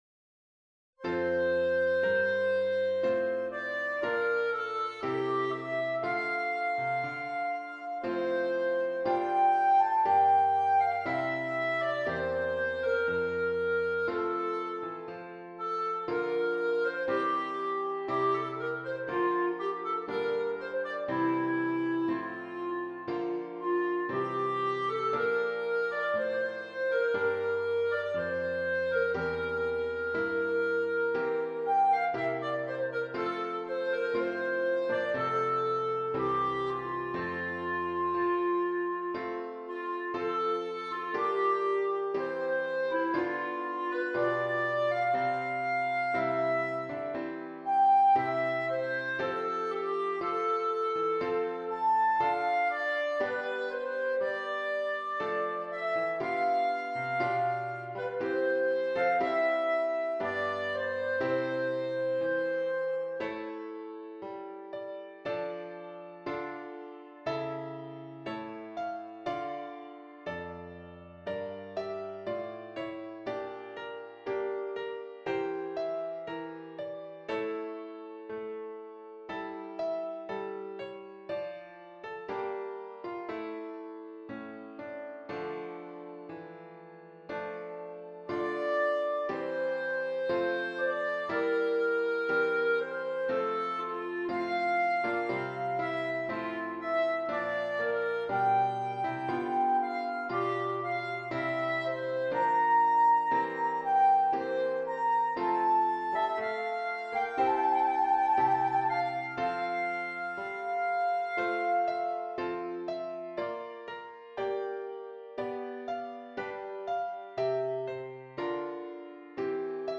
Clarinet and Keyboard